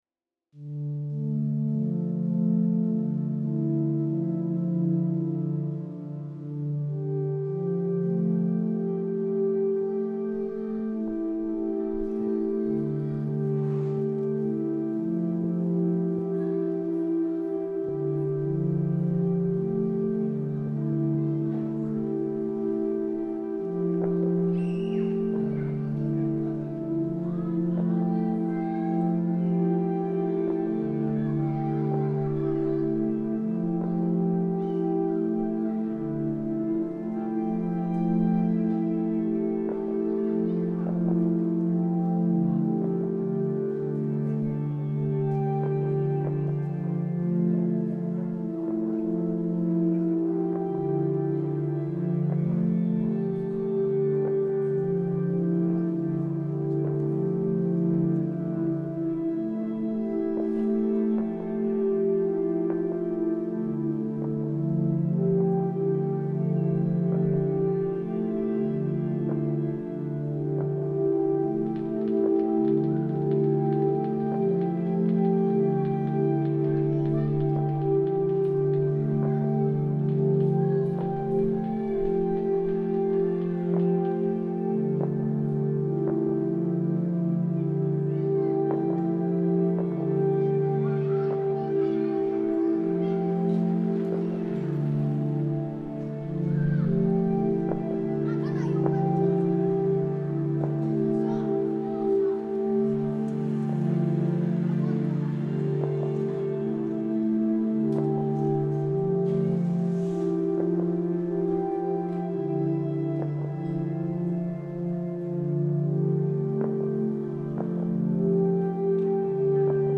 village fireworks reimagined